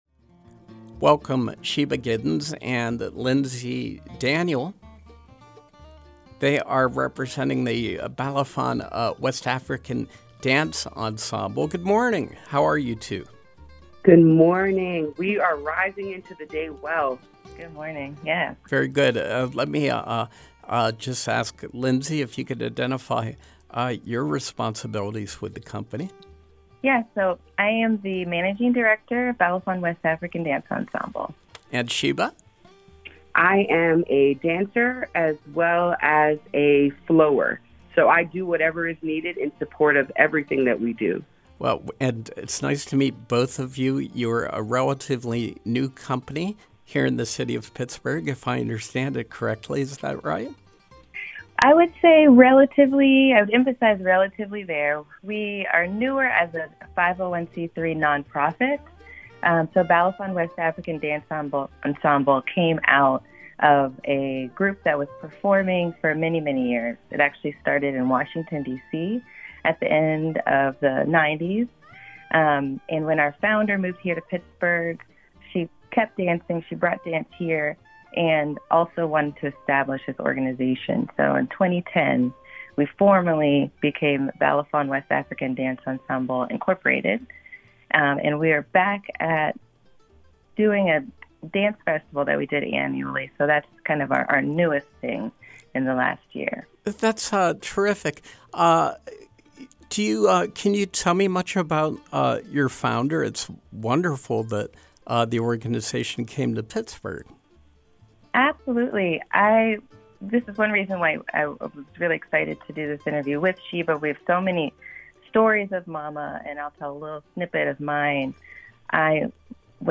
Interview: Balafon’s Black River African Dance Festival